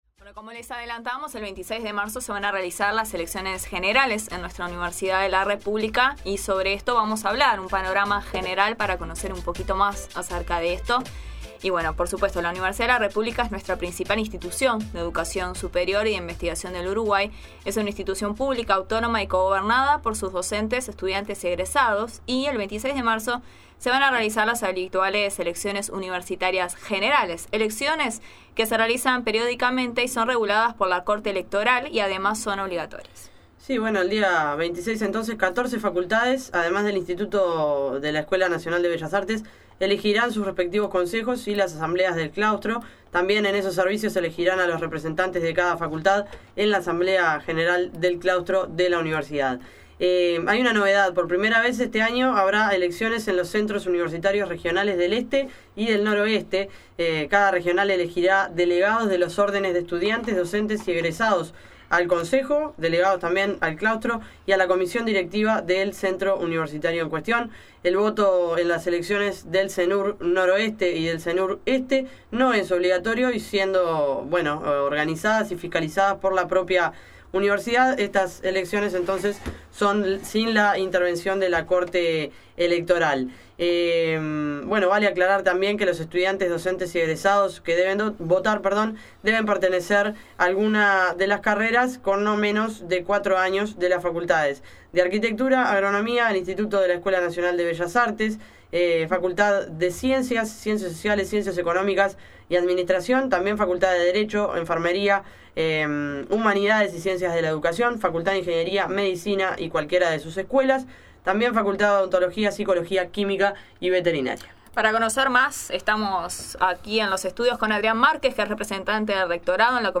La Nueva Mañana conversó en vivo